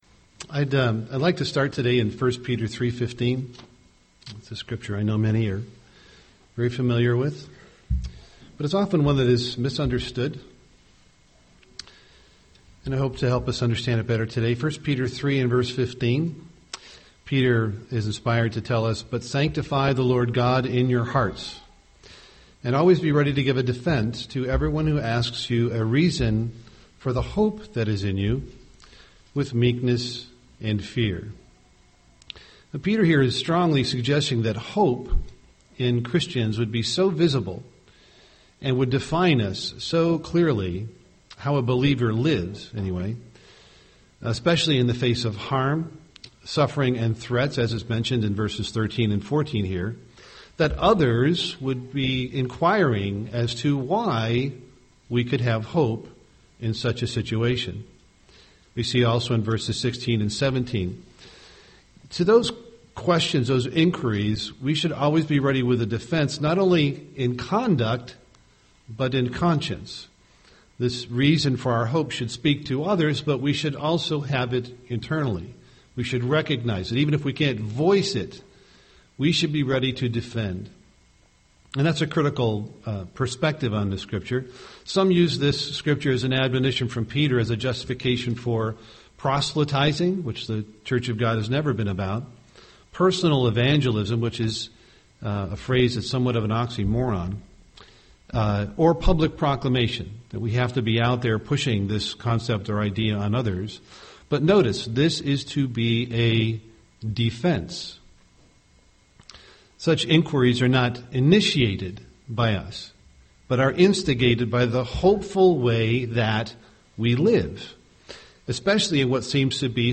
UCG Sermon hope learn reason plan Studying the bible?